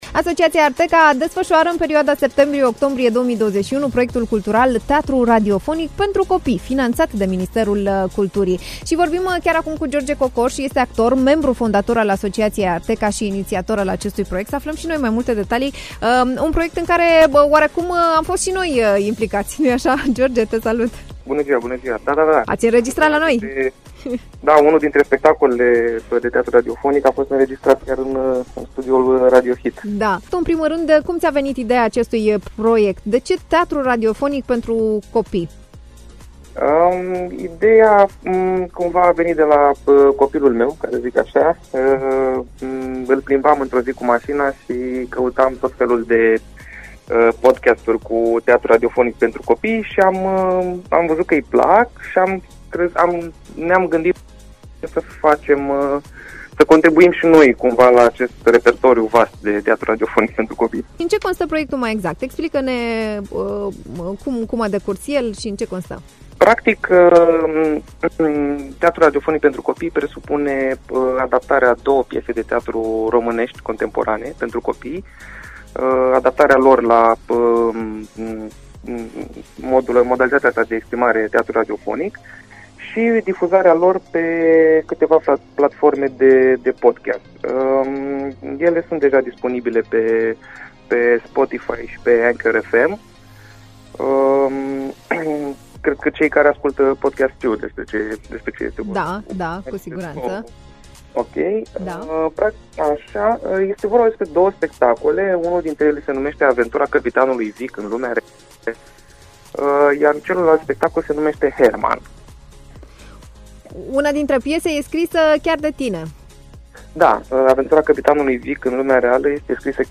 Radio Hit